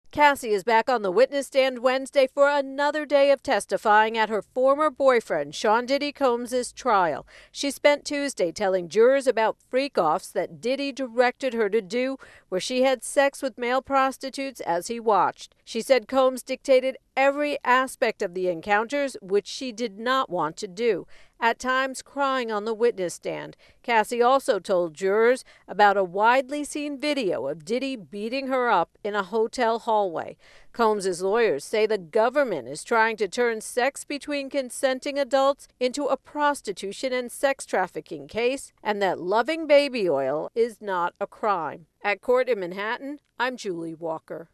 reports from court on testimony at Sean 'Diddy' Combs’ trial, where ex-girlfriend Cassie Ventura has testified about abuse and 'freak offs.'